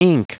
Prononciation du mot ink en anglais (fichier audio)
Prononciation du mot : ink